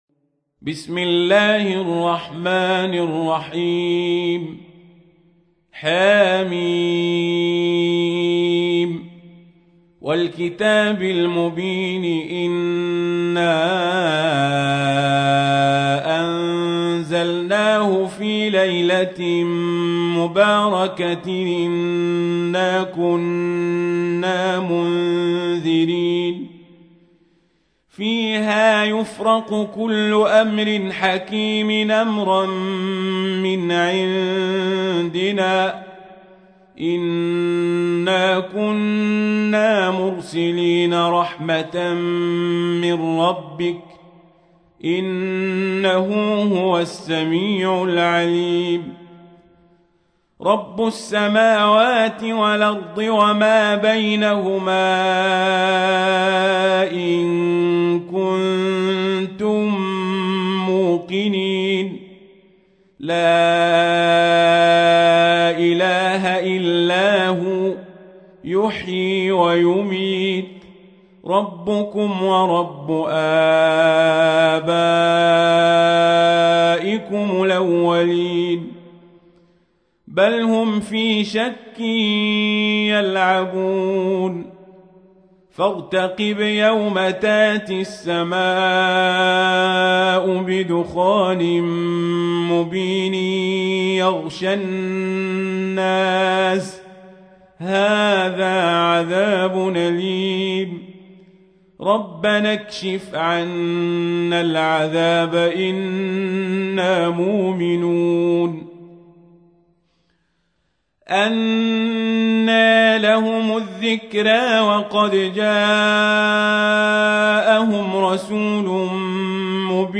تحميل : 44. سورة الدخان / القارئ القزابري / القرآن الكريم / موقع يا حسين